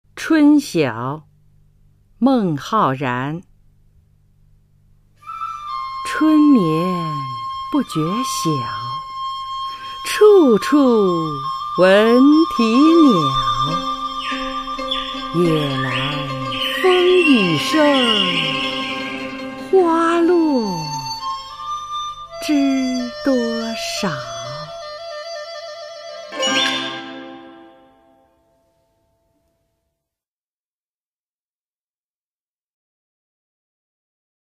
[隋唐诗词诵读]孟浩然-春晓（女） 配乐诗朗诵